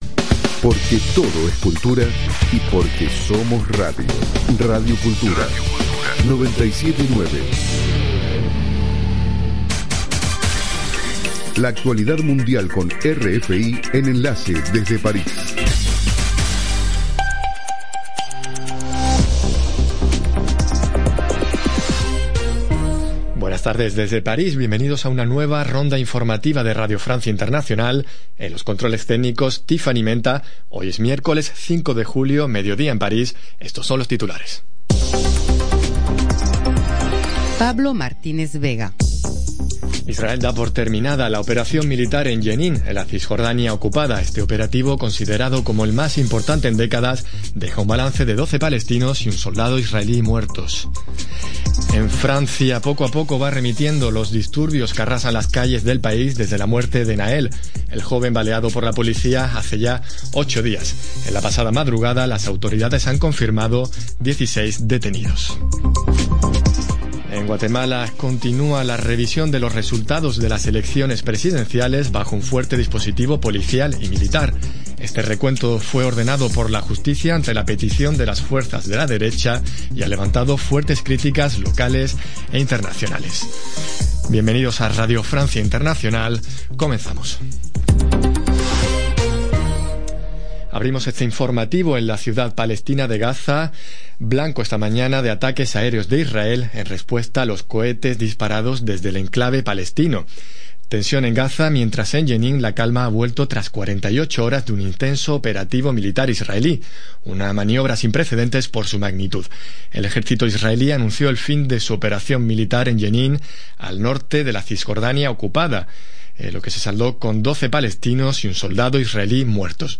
Programa: RFI – Noticiero de las 07:00 Hs.